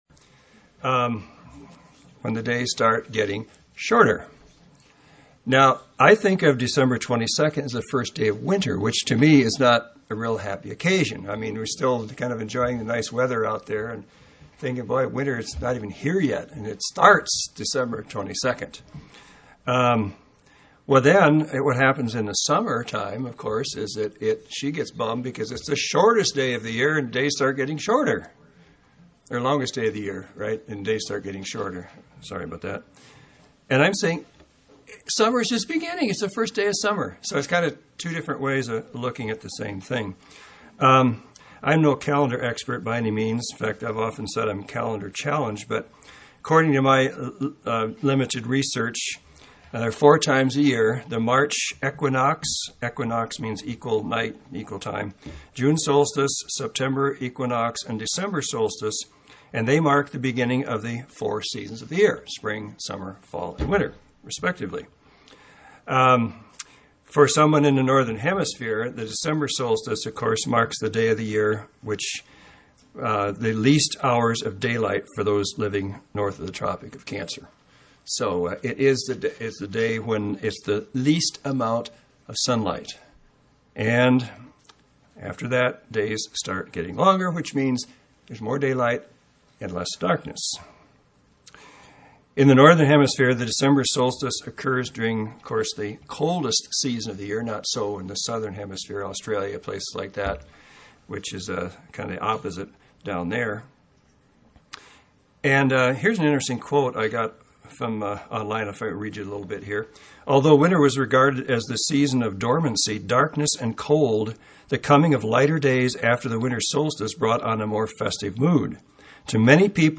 The Bible also has a lot to say about the meaning and importance of light and darkness. This message is designed to enlighten you with some of these truths, so you won't be in the dark.